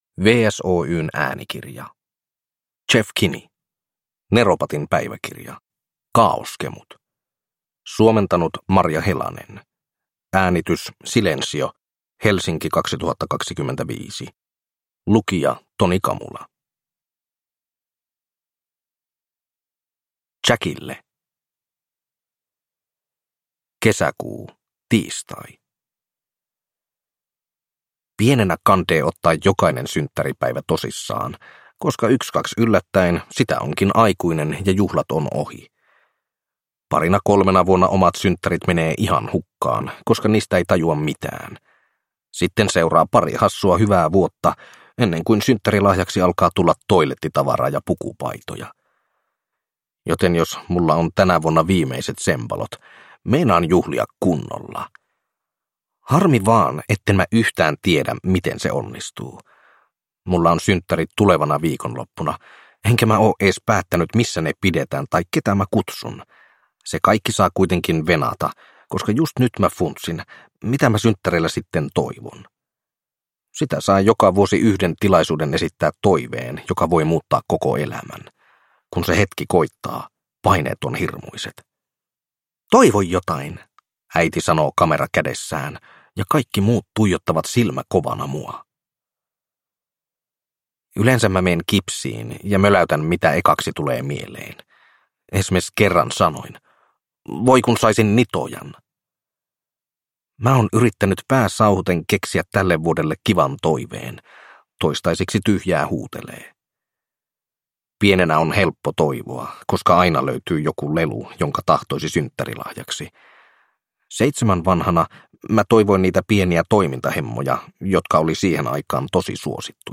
Neropatin päiväkirja: Kaaoskemut – Ljudbok